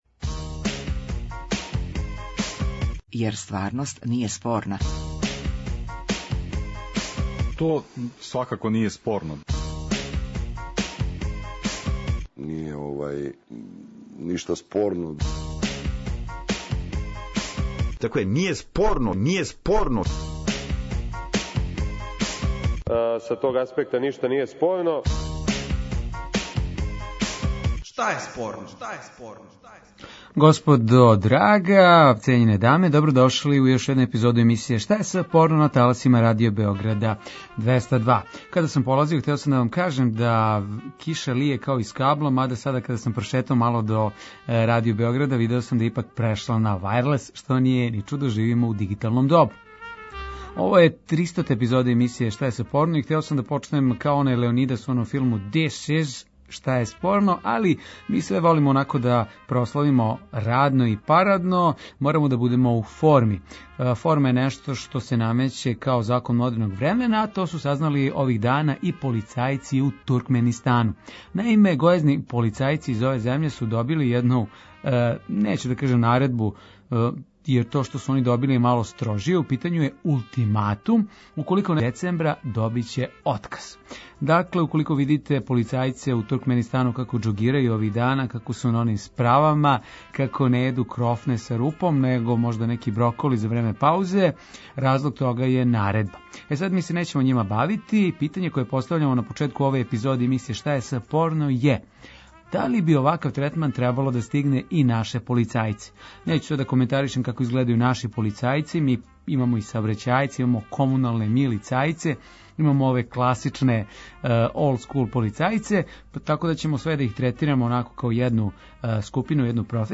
Радијски актуелно - забавни кабаре интерактивног карактера.